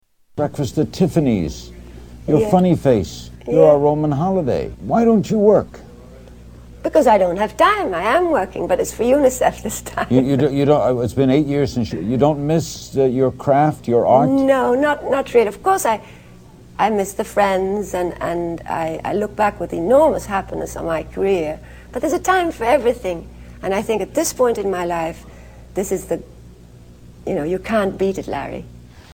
Interview with Larry King
Tags: Audrey Hepburn clips Audrey Hepburn interview Audrey Hepburn audio Audrey Hepburn Actress